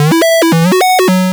retro_synth_beeps_05.wav